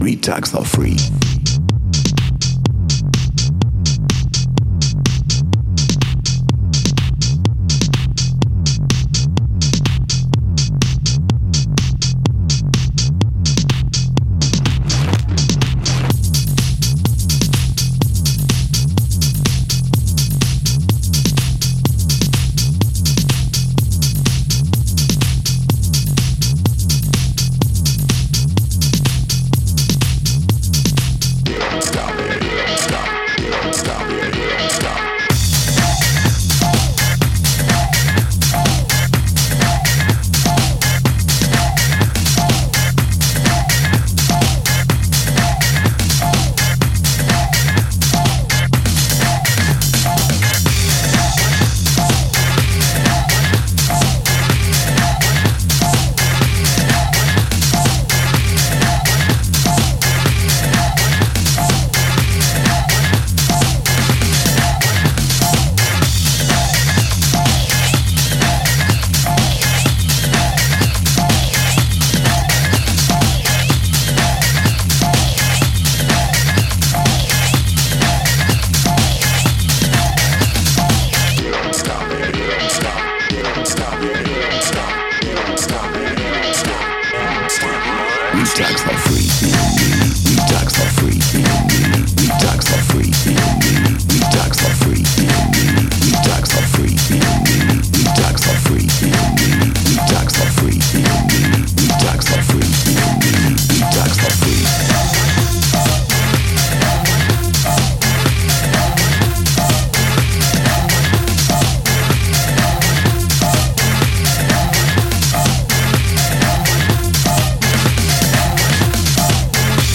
Styl: House, Techno, Breaks/Breakbeat